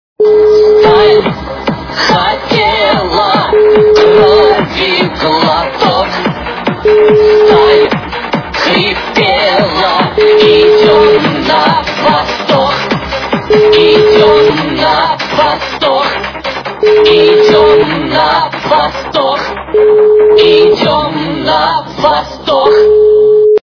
- Remix
При заказе вы получаете реалтон без искажений.